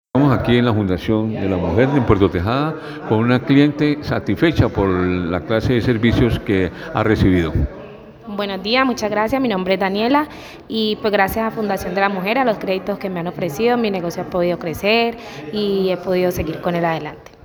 CLIENTE-fdlm-Puerto-Tejada-La-Suprema-Fm.mp3